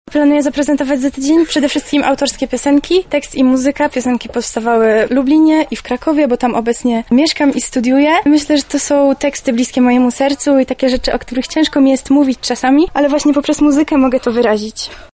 O nadchodzącym występie mówi sama artystka: